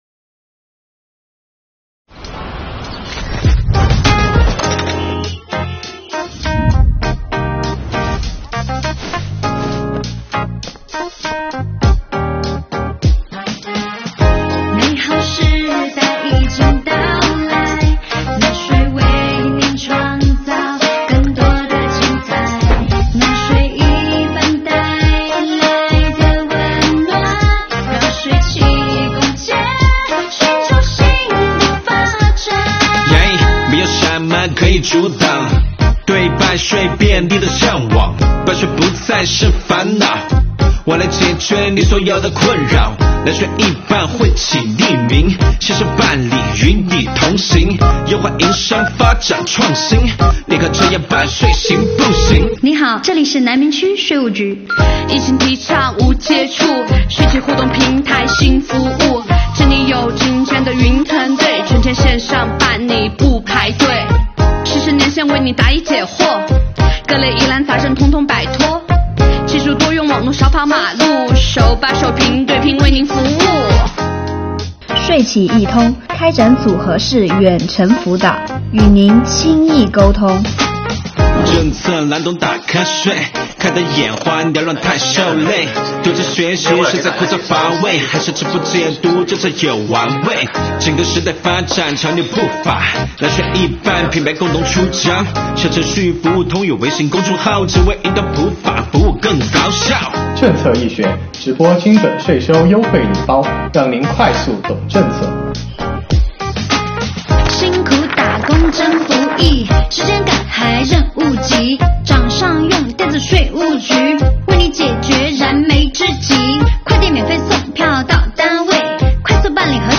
快跟随超燃节奏看看吧！